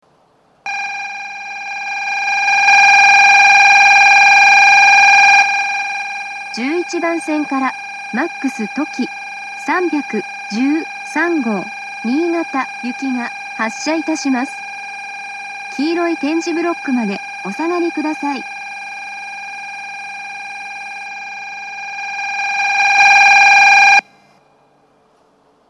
２０２１年７月中旬にはCOSMOS連動の放送が更新され、HOYA製の合成音声による放送になっています。
１１番線発車ベル Ｍａｘとき３１３号新潟行の放送です。
１１番線は必ずベルがフェードインするようです。